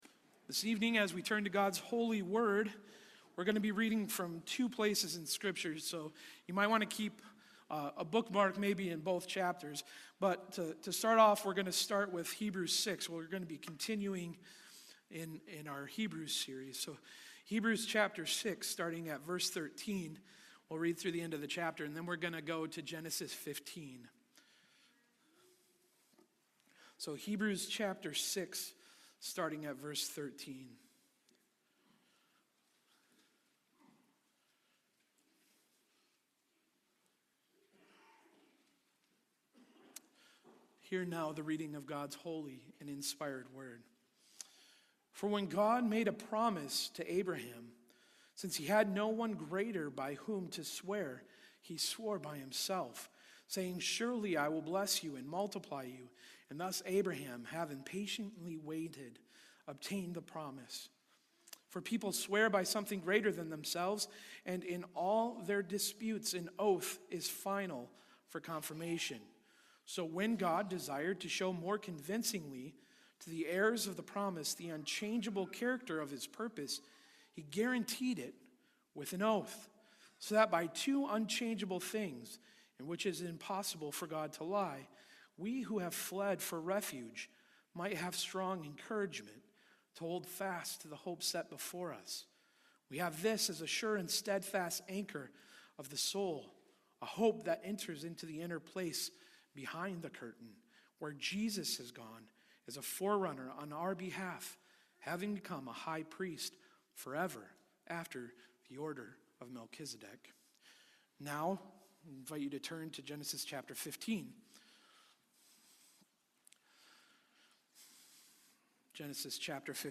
Our Sure and Steadfast Anchor | SermonAudio Broadcaster is Live View the Live Stream Share this sermon Disabled by adblocker Copy URL Copied!